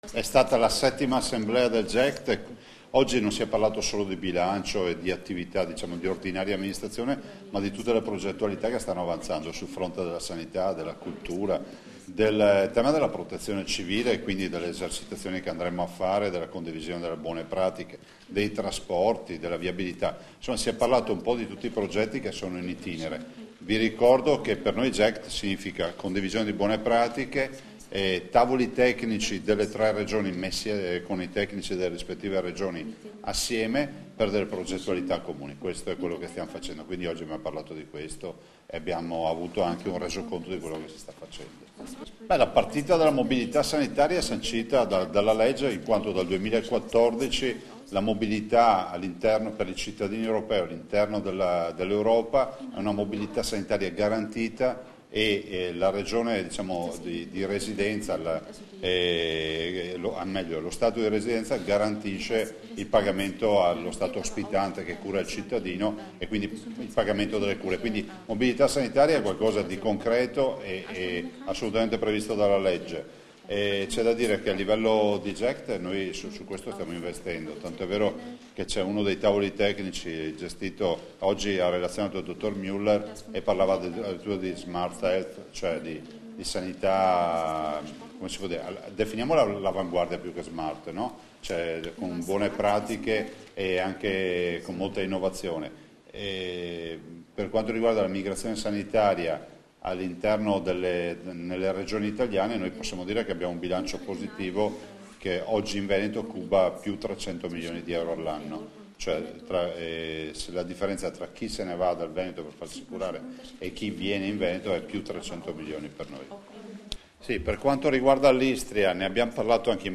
Dichiarazioni di Luca Zaia (Formato MP3) [2607KB]
a margine dell'ottava assemblea del Gruppo Europeo di Cooperazione Territoriale (GECT) Euregio Senza Confini, rilasciate a Trieste il 23 maggio 2016